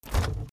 冷蔵庫 開ける
/ M｜他分類 / L10 ｜電化製品・機械